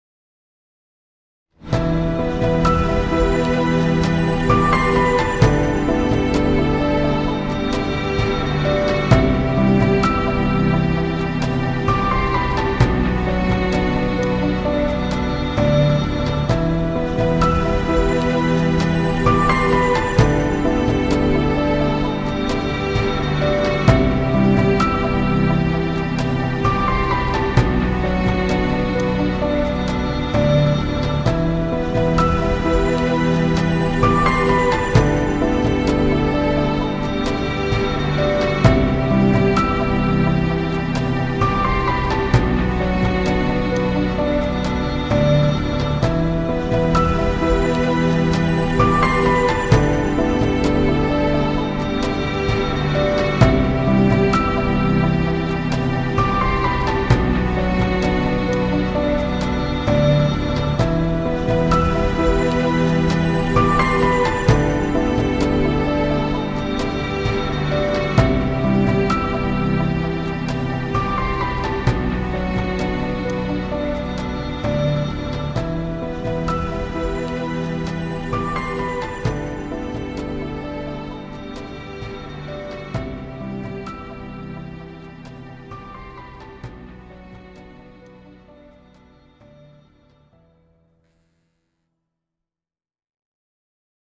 tema dizi müziği, duygusal huzurlu rahatlatıcı fon müzik.